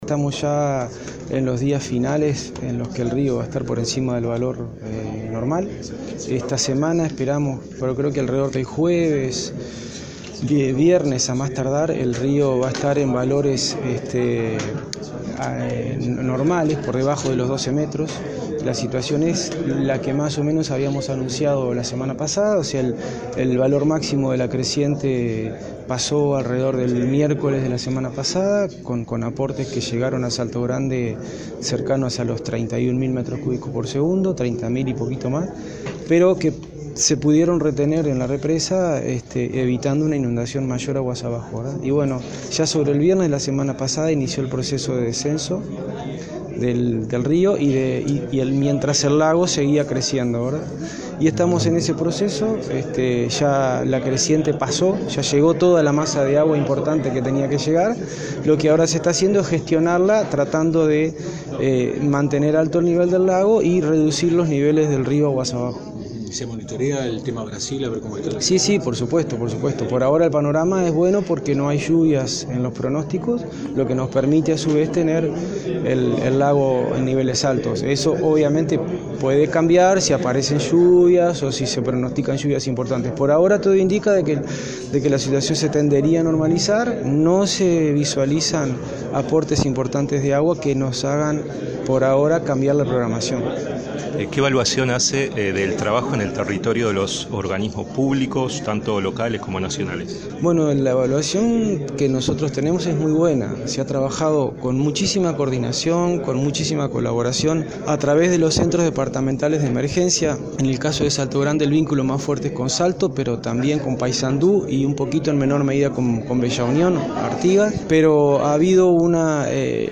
Este jueves y viernes, el río Uruguay volverá a valores normales, por debajo de los 12 metros, aseguró el presidente de la delegación uruguaya en la Comisión Técnico Mixta Salto Grande, Gabriel Rodríguez. Destacó el trabajo coordinado entre las instituciones de Estado en el territorio para ayudar a las personas desplazadas en el Norte del país.